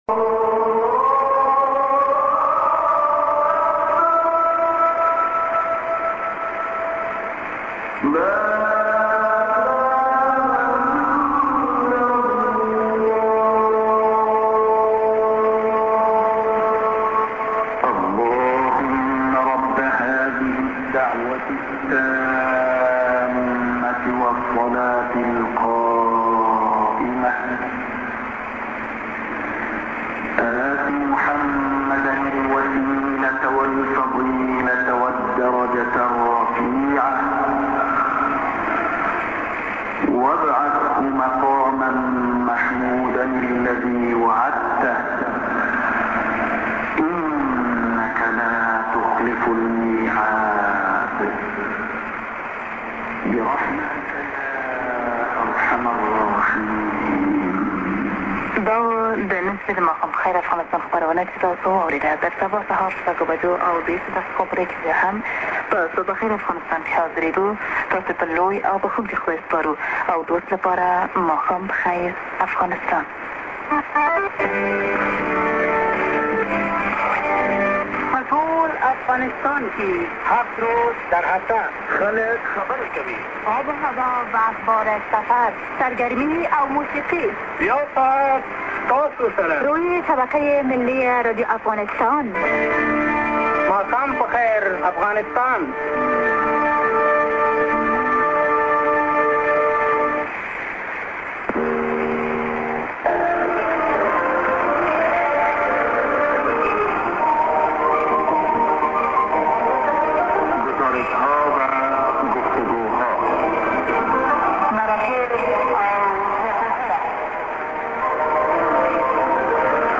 St. koran->ID(women+man)->